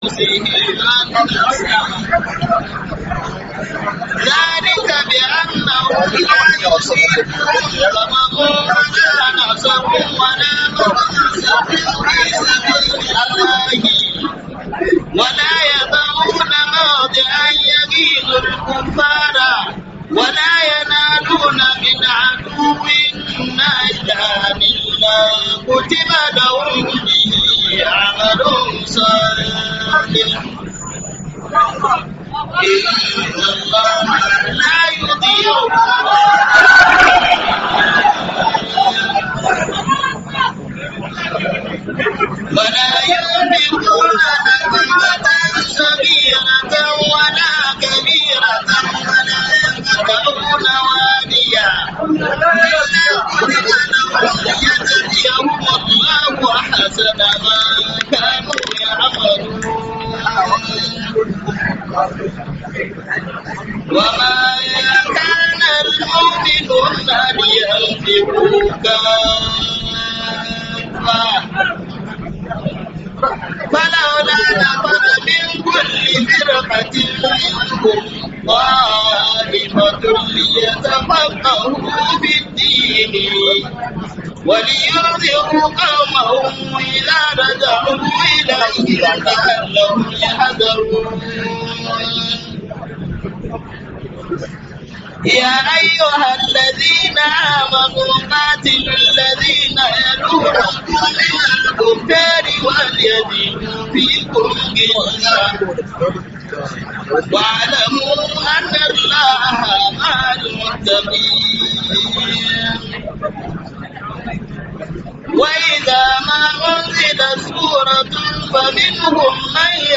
Waazin Gombe 2025